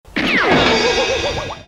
ethyethiiywaithy 1 Meme Sound Effect
Category: Reactions Soundboard